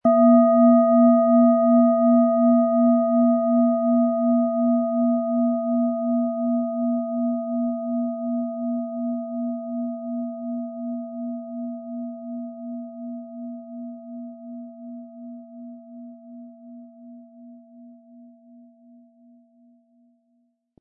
Planetenschale® Wohlige Energie im Brustbereich & Sich selbst helfen können mit Hopi-Herzton & Mond, Ø 13,4 cm inkl. Klöppel
• Mittlerer Ton: Mond
Im Sound-Player - Jetzt reinhören hören Sie den Original-Ton dieser Schale.
PlanetentöneHopi Herzton & Mond
SchalenformBihar
MaterialBronze